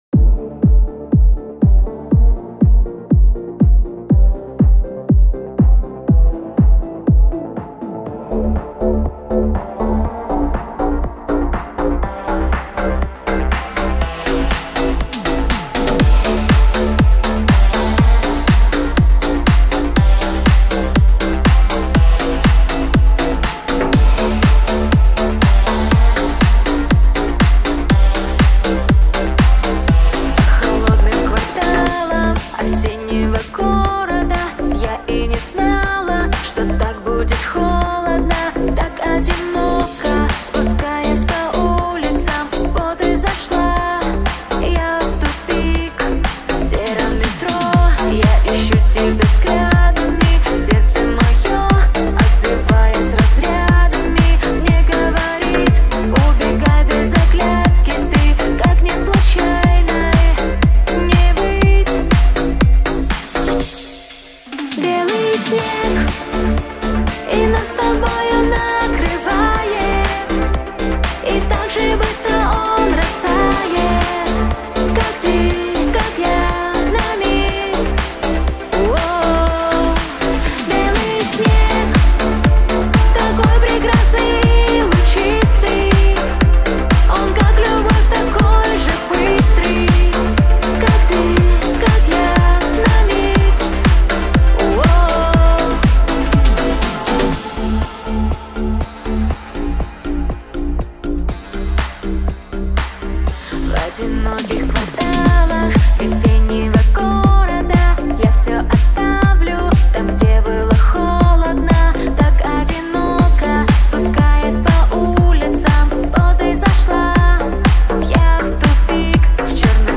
Club Mix